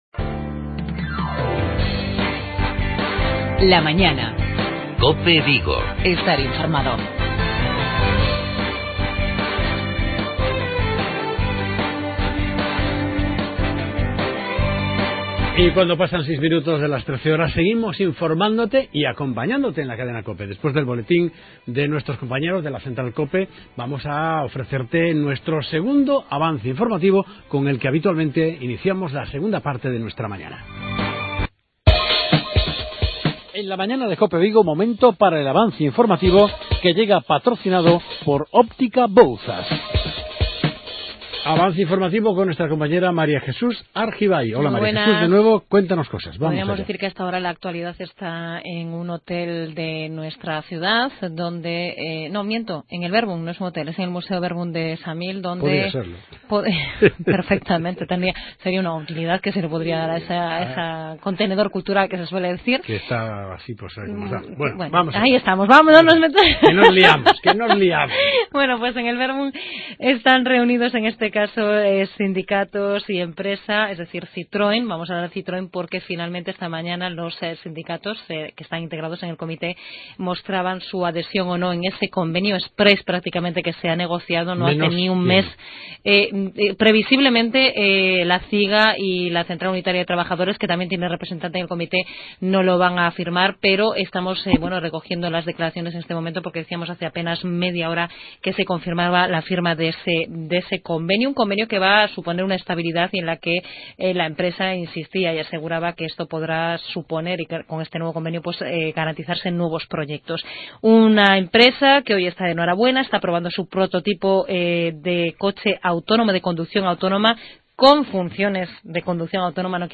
AUDIO: TERTULIA DEPORTIVA